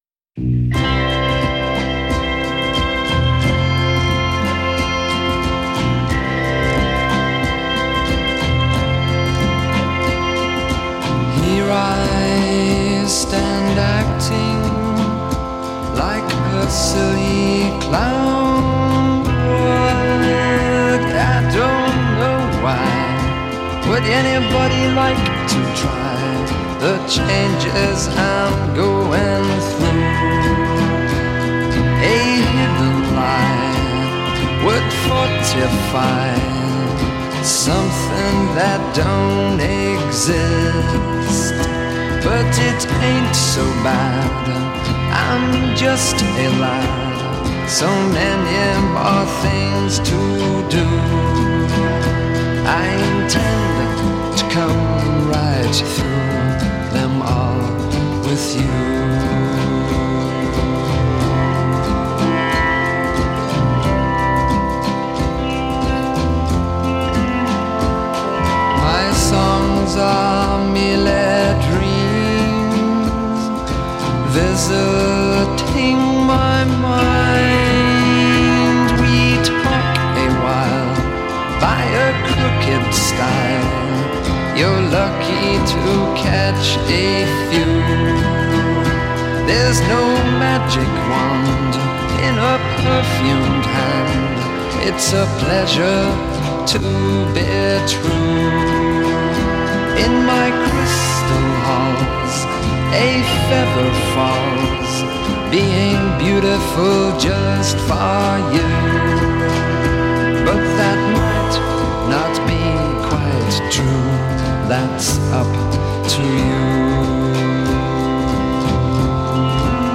Smell the patchouli on this one…